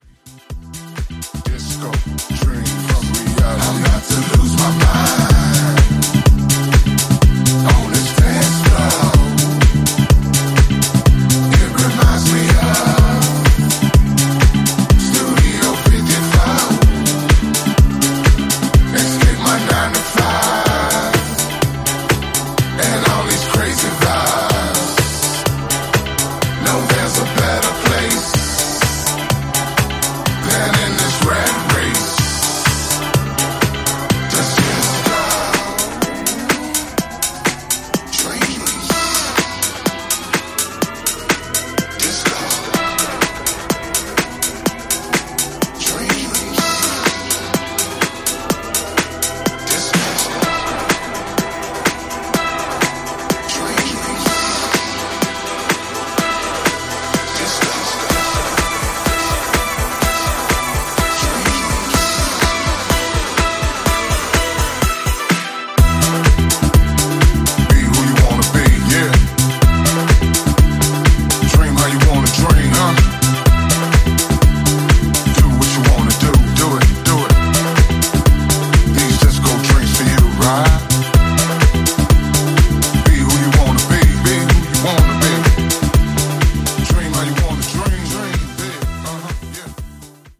on vocals
jackin’ house